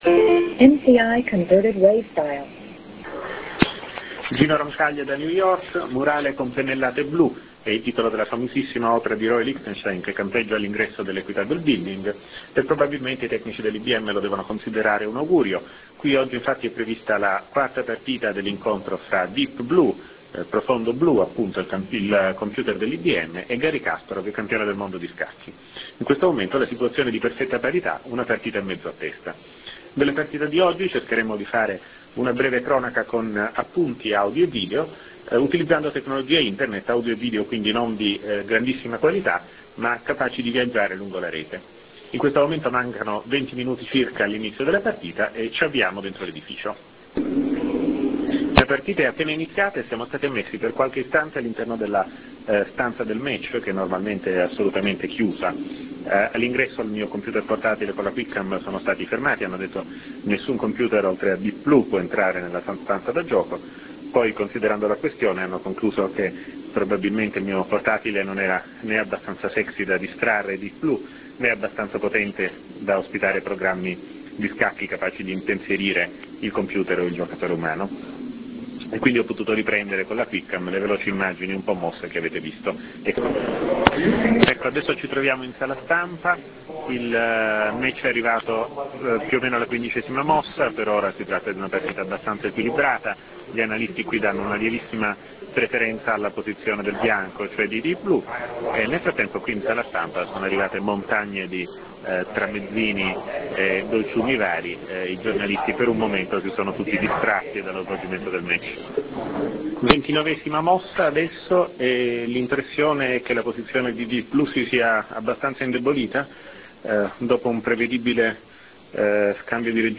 Commento audio